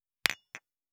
273食器をぶつける,ガラスをあてる,
コップ効果音厨房/台所/レストラン/kitchen食器
コップ